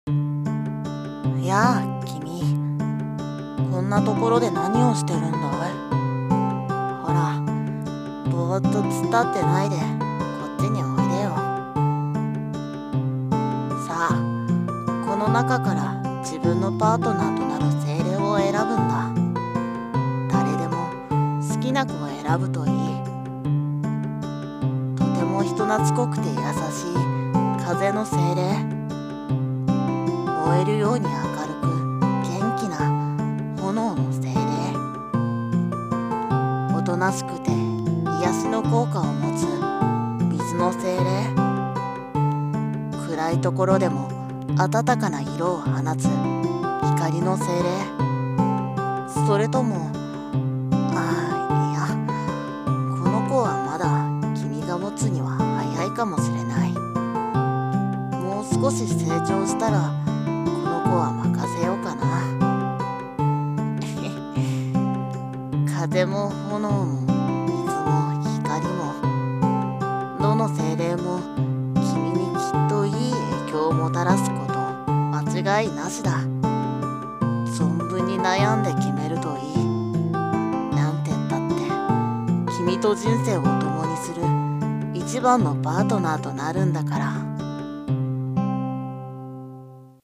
【一人声劇】最高のパートナーを